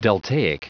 Prononciation du mot deltaic en anglais (fichier audio)
Prononciation du mot : deltaic